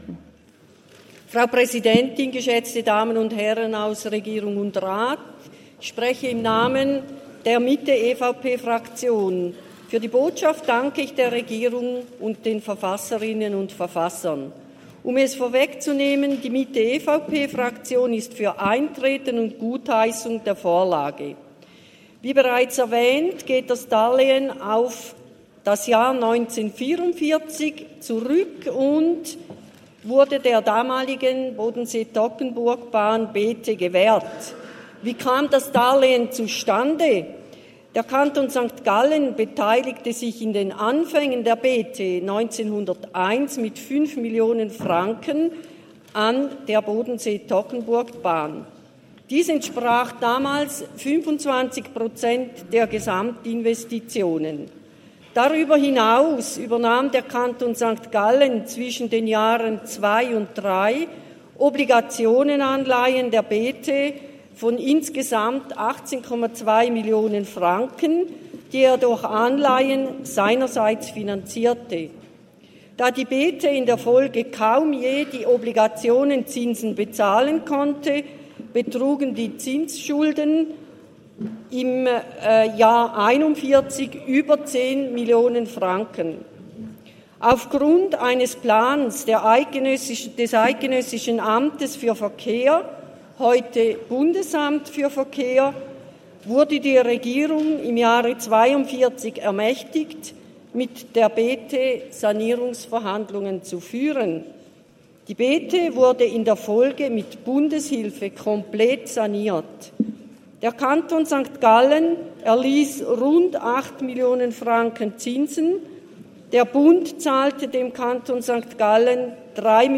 Session des Kantonsrates vom 27. bis 29. November 2023, Wintersession
27.11.2023Wortmeldung